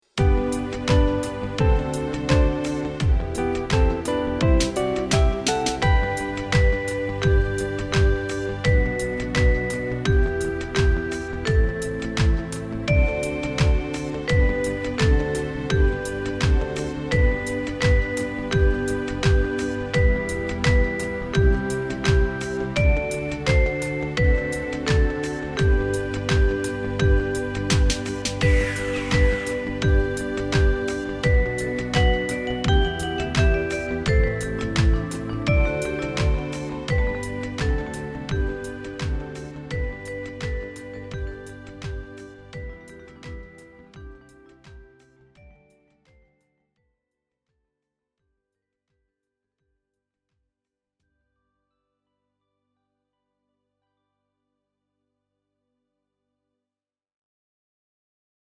Maza dziesmiņa Play-along.
Spied šeit, lai paklausītos Demo ar melodiju